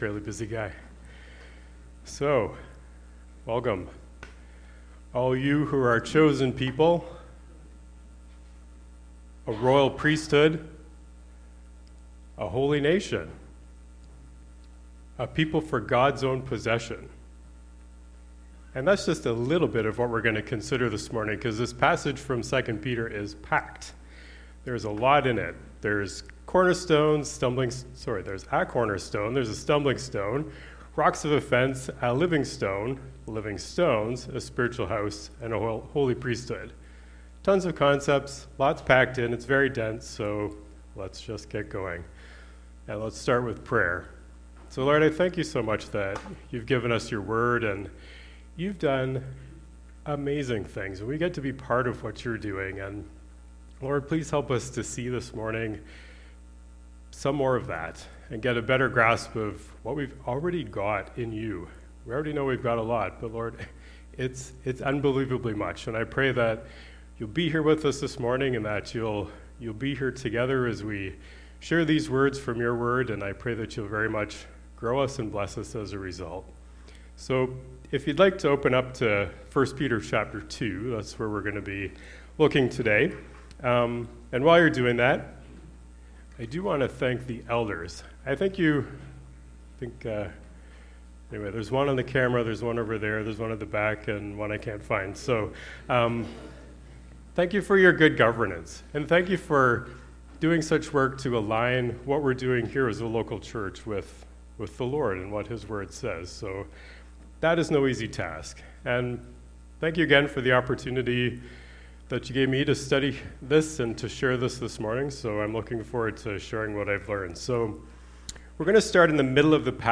Sermon Audio and Video